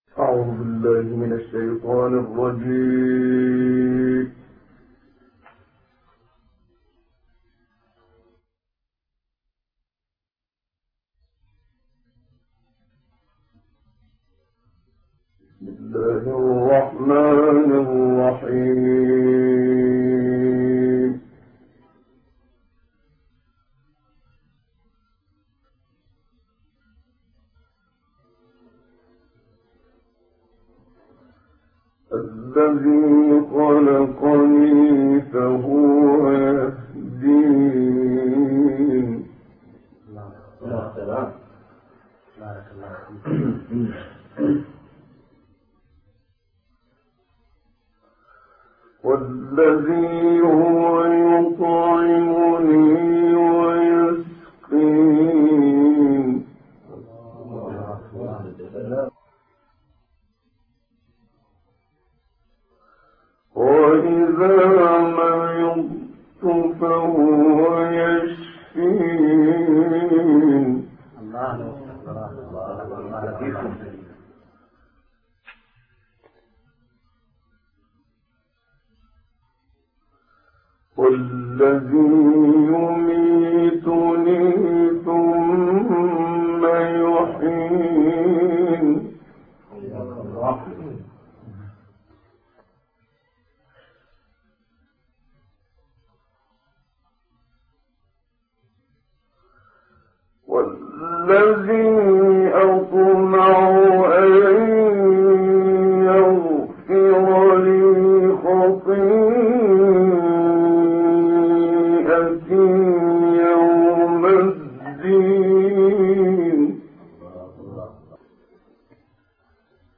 عنوان المادة 026 الشورى 78-89 الرحمن 1-9 تلاوات نادرة بصوت الشيخ محمد صديق المنشاوي تاريخ التحميل السبت 11 نوفمبر 2023 مـ حجم المادة 5.71 ميجا بايت عدد الزيارات 115 زيارة عدد مرات الحفظ 67 مرة إستماع المادة حفظ المادة اضف تعليقك أرسل لصديق